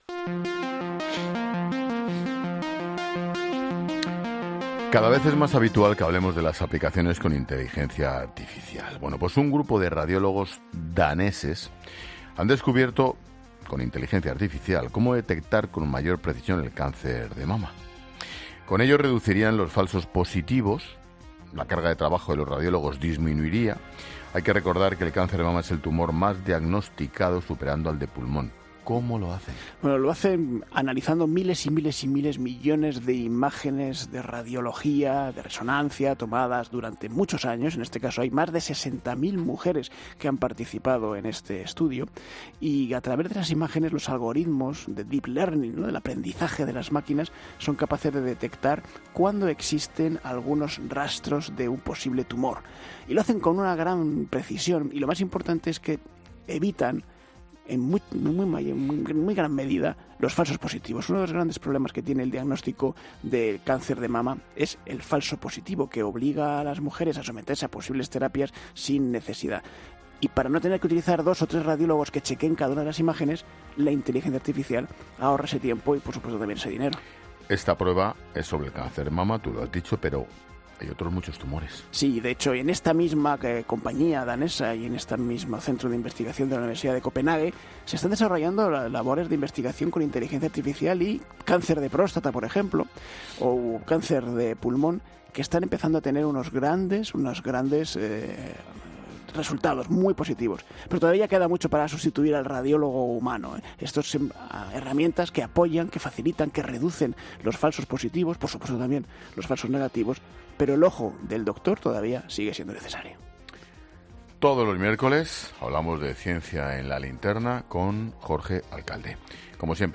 Y lo hace precisamente, como explica Ángel Expósito en la sección de ciencia de este miércoles en La Linterna, se trata del tumor más diagnosticado cada año en el mundo, por lo que el descubrimiento del centro danés resulta clave.